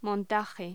Locución: Montaje
voz
Sonidos: Hostelería